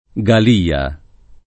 DOP: Dizionario di Ortografia e Pronunzia della lingua italiana
[ g al & a ]